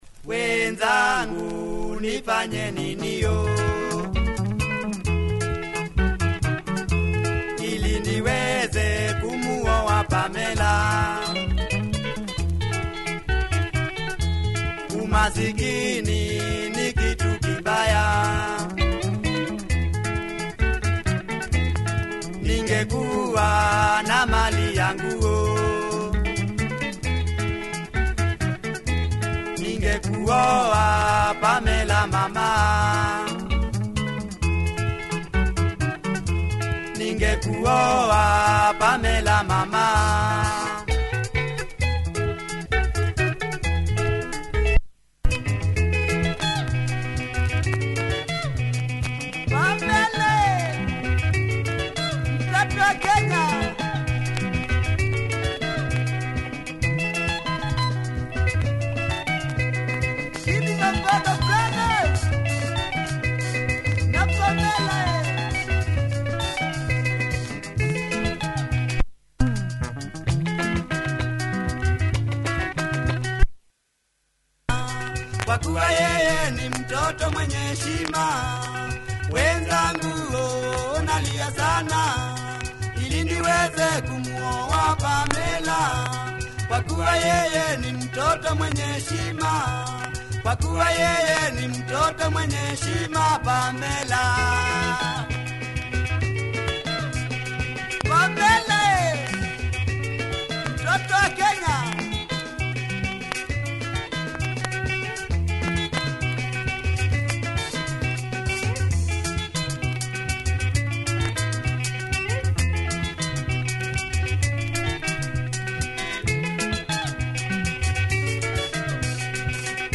Quality Kamba benga mover